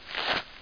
gravelshuffle1.mp3